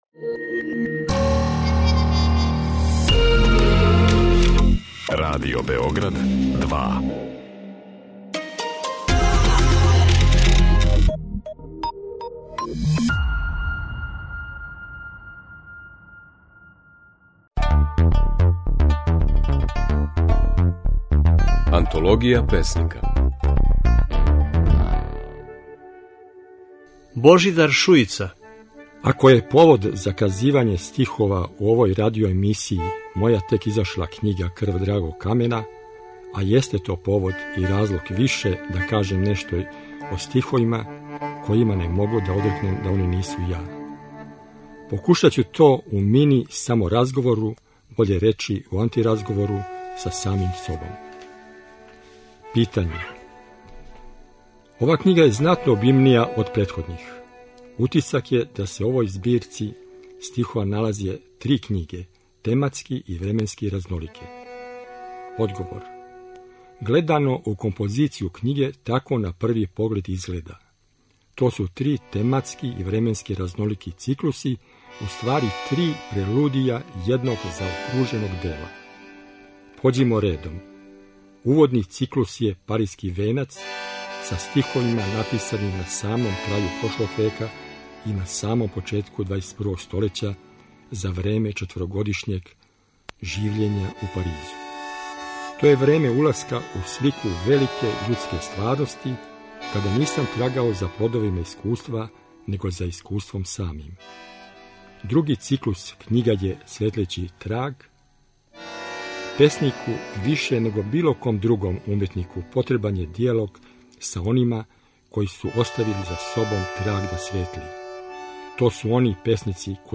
Емитујемо снимке на којима своје стихове говоре наши познати песници